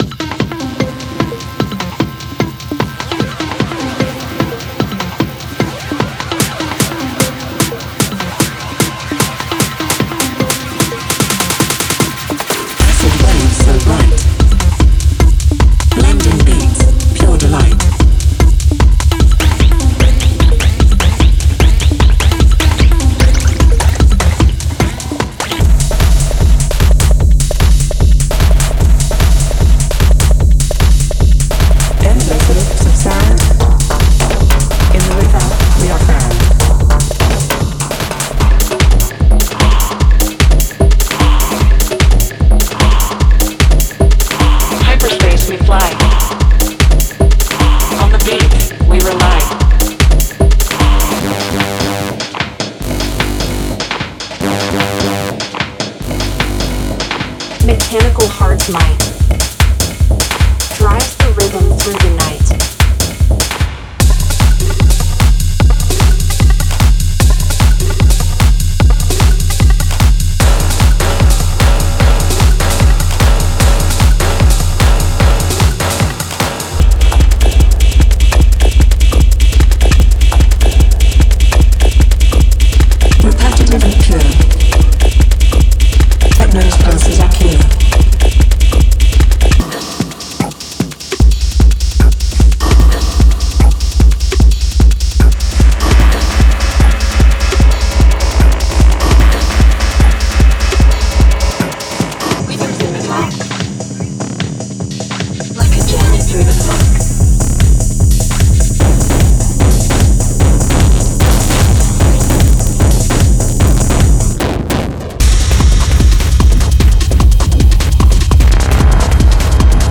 テクノサンプルパック
デモサウンドはコチラ↓
Genre:Techno